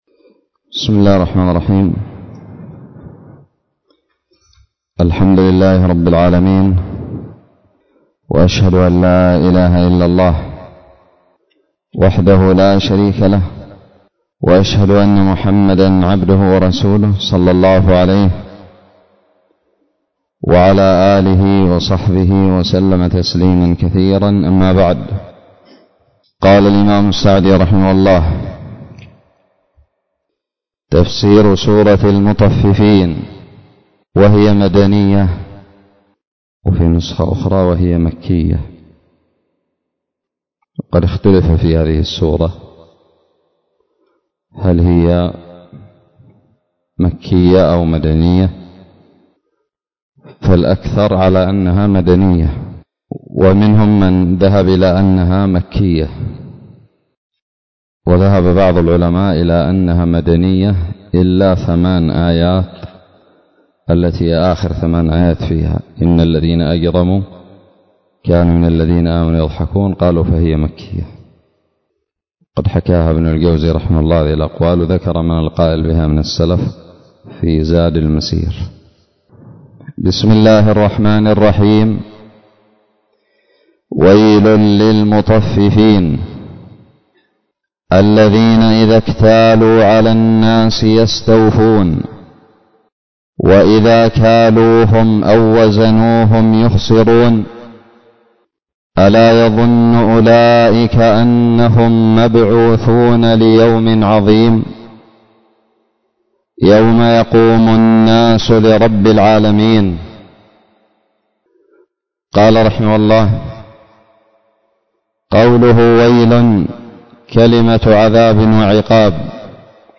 الدرس الأول من تفسير سورة المطففين
ألقيت بدار الحديث السلفية للعلوم الشرعية بالضالع